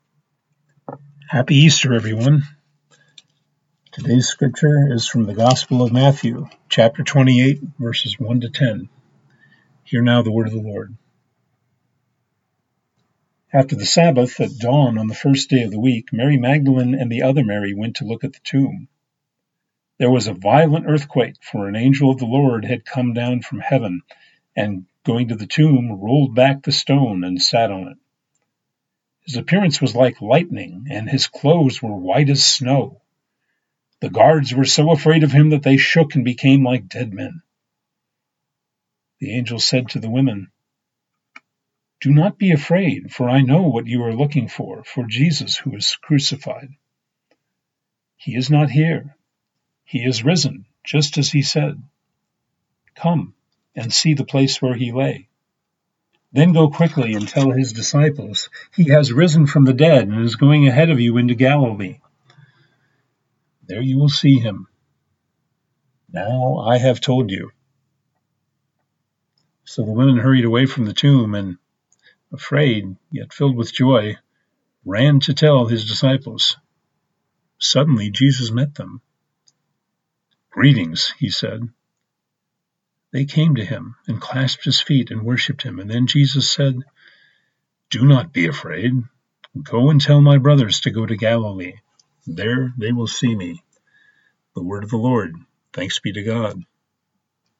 Easter-Scripture-Matt-28-1-10-Special-Delivery.mp3